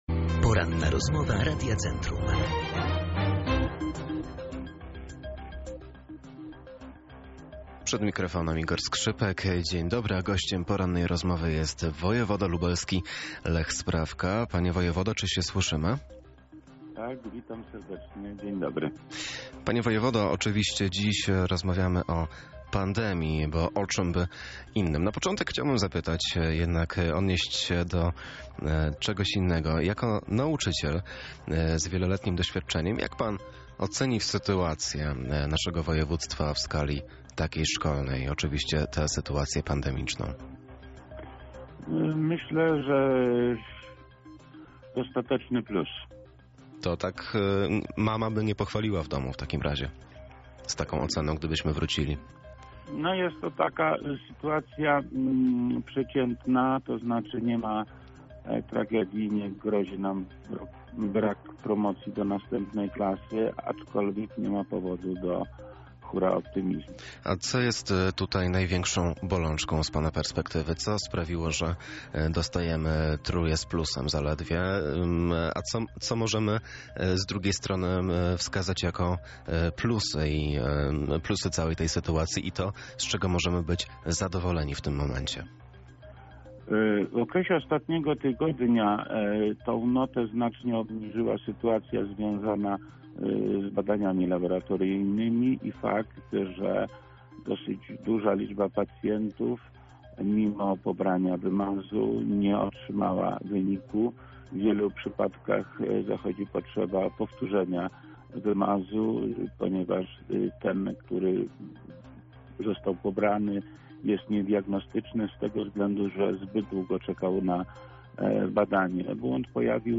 Jak podkreślał w Porannej rozmowie Radia Centrum, notę obniża przede wszystkim sytuacja w laboratoriach: